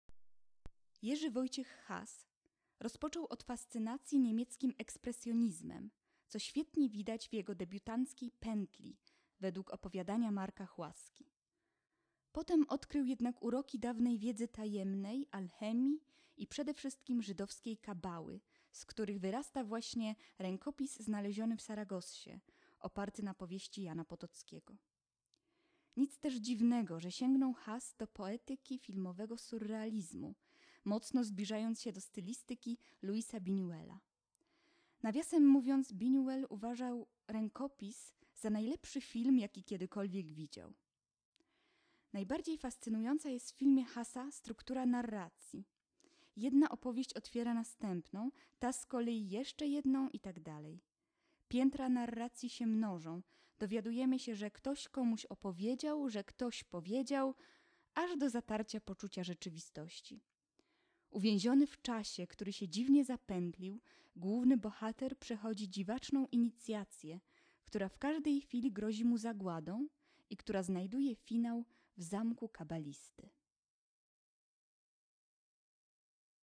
włącz  lektor